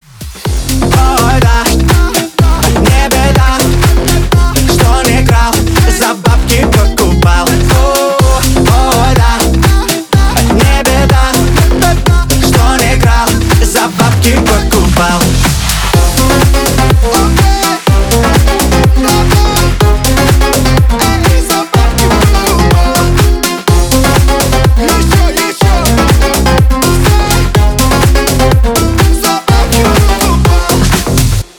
• Качество: 320, Stereo
зажигательные
Club House
Dance Pop
Танцевальный ремикс песни